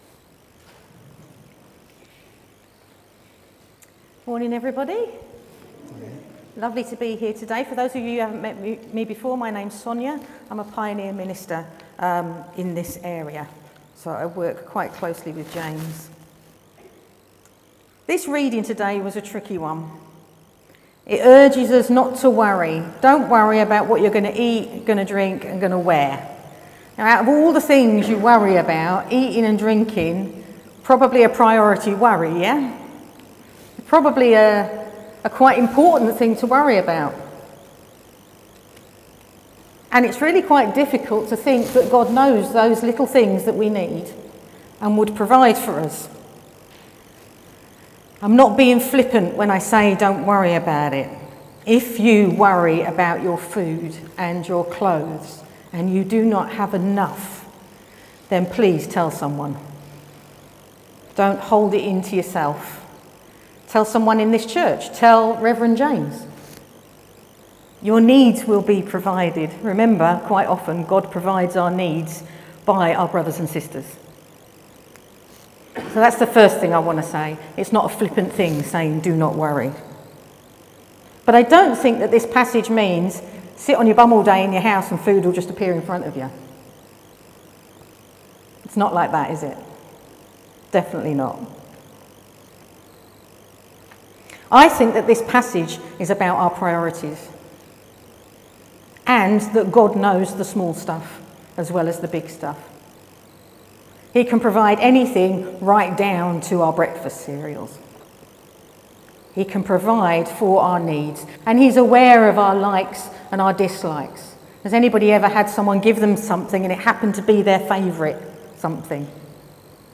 Sermon: Seek first the kingdom | St Paul + St Stephen Gloucester